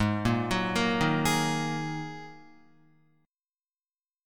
Ab7sus2 Chord